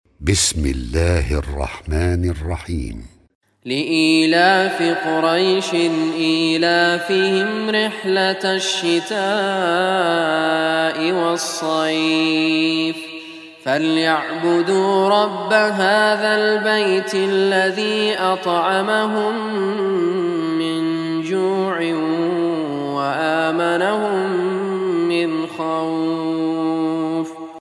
Surah Quraish, listen or play online mp3 tilawat / recitation in Arabic in the beautiful voice of Sheikh Raad Al Kurdi.